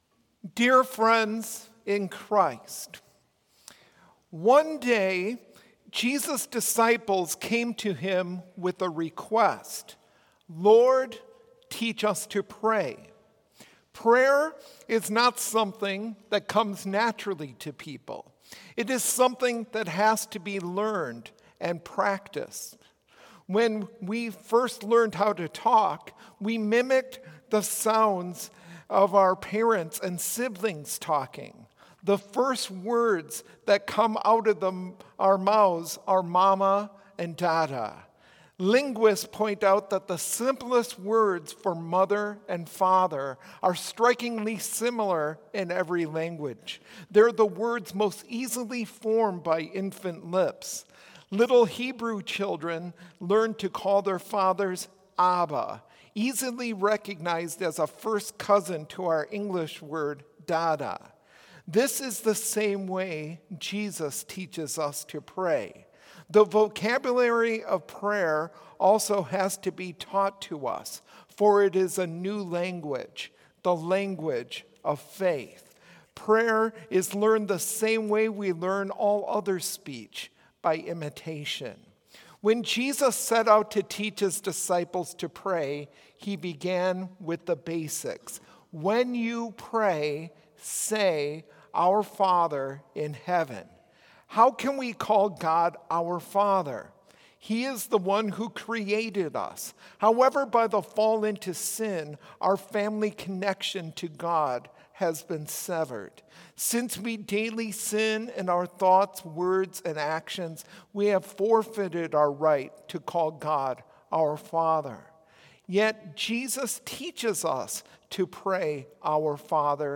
Complete service audio for Chapel - Tuesday, May 7, 2024